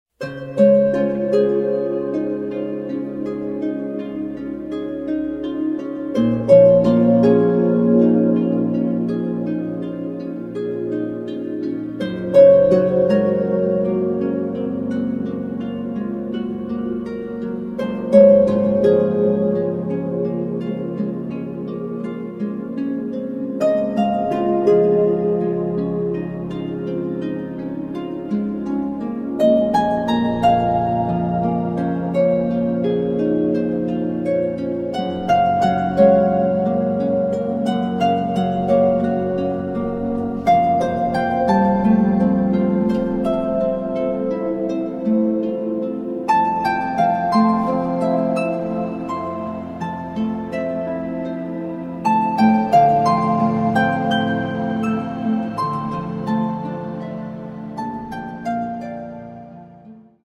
is for pedal harp solo.